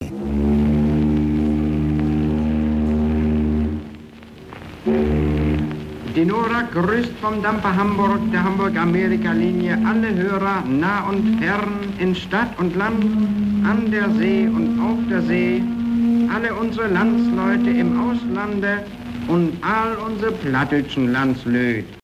Seine Begrüßung der Hörerinnen und Hörer vom "Dampfer Hamburg" ist